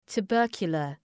Προφορά
{tu:’bɜ:rkjələr}